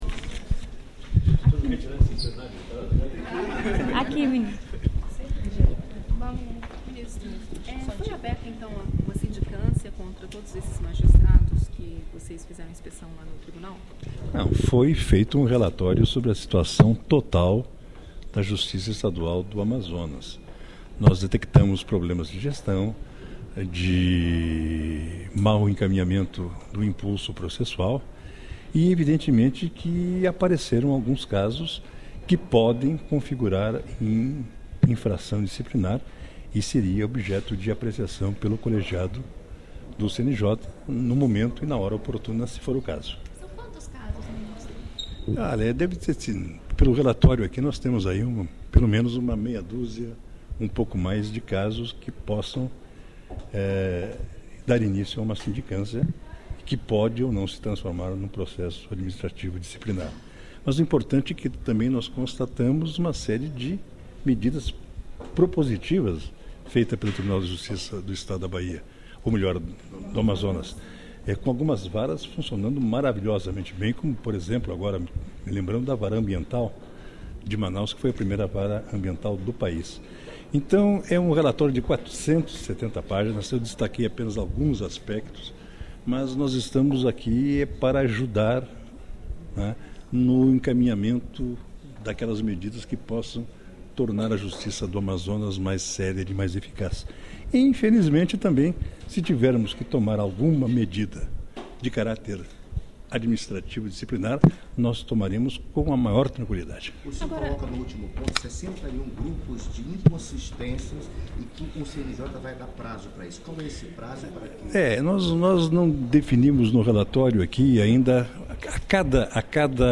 aqui a entrevista coletiva concedida pelo ministro Gilson Dipp.